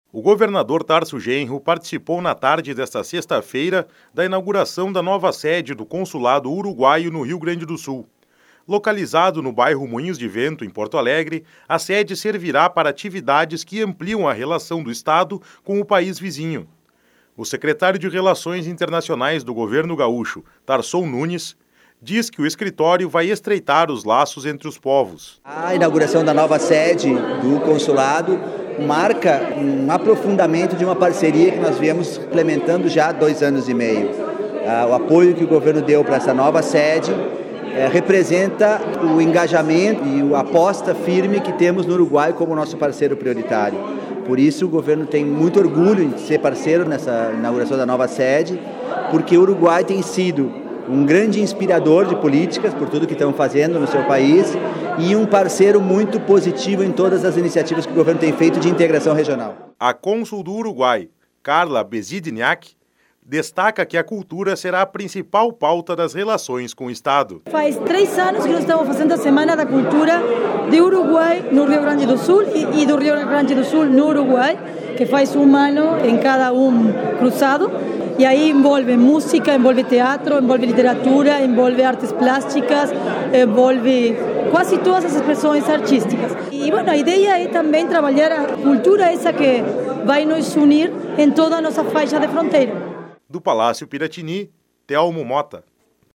Governador participa de inauguração de consulado do Uruguai em Porto Alegre
O governador Tarso Genro participou, na tarde desta sexta-feira (4), da inauguração da nova sede do consulado uruguaio no Rio Grande do Sul.